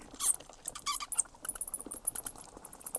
bat2.ogg